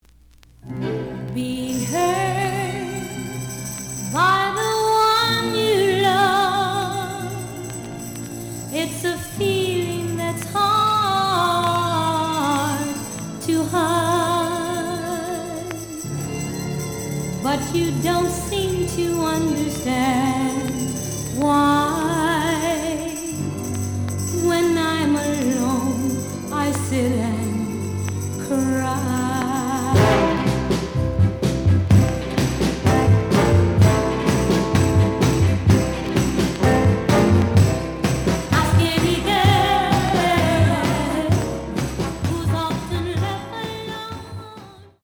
試聴は実際のレコードから録音しています。
●Genre: Soul, 60's Soul